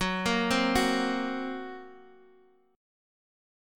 F#Mb5 chord